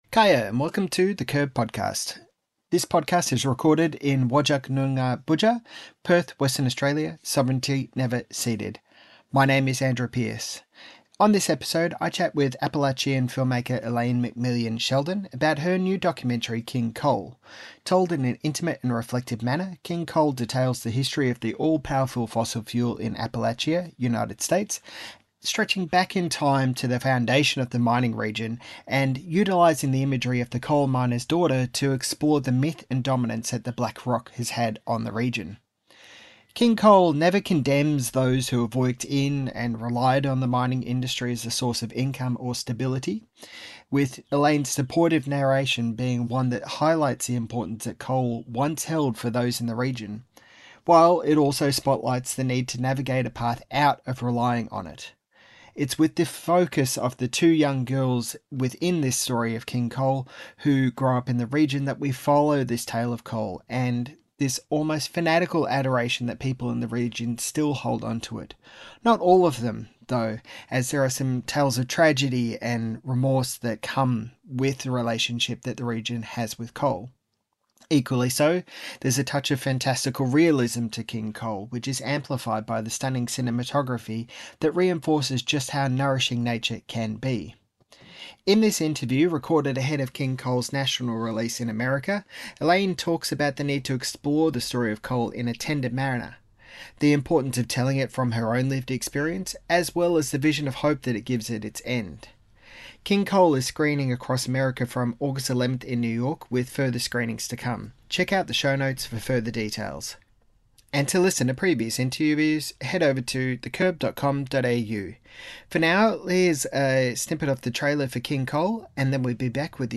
This Interview - The Curb